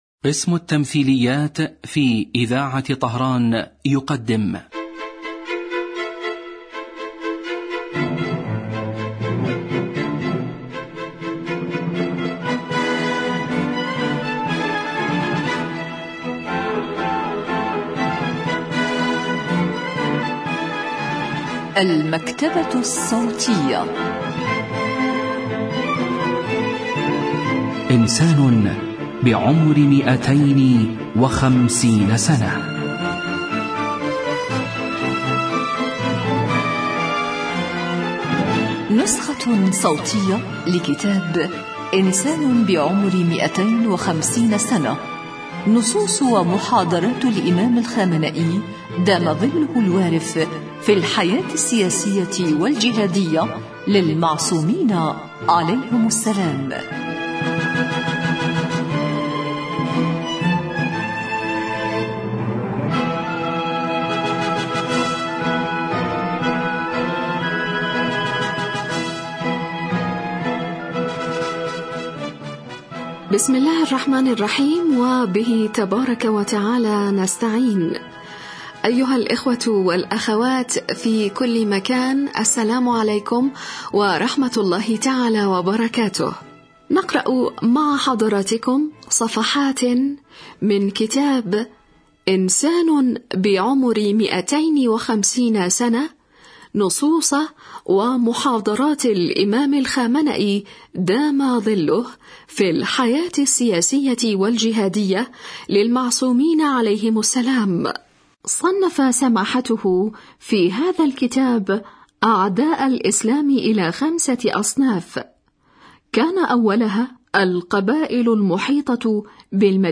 إذاعة طهران- إنسان بعمر 250 سنة: نسخة صوتية لكتاب إنسان بعمر 250 سنة للسيد علي الخامنئي في الحياة السياسية والجهادية للمعصومين عليهم السلام.